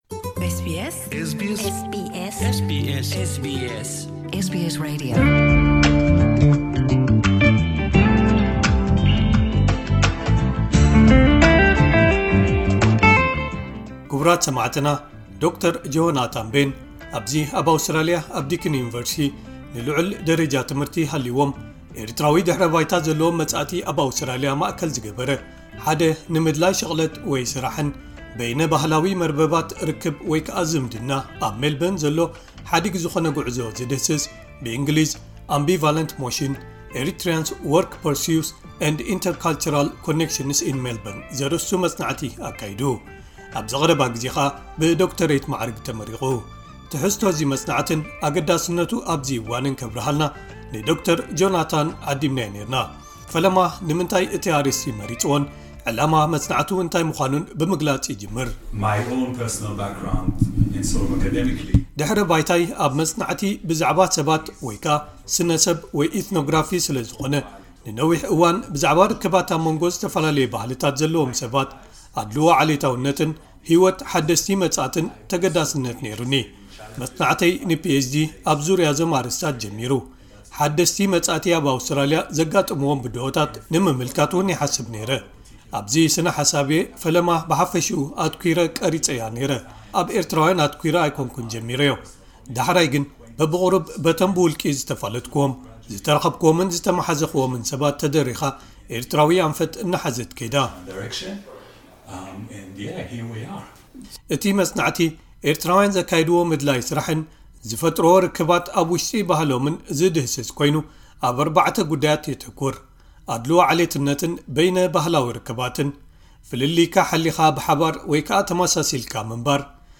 ቃለመሕትት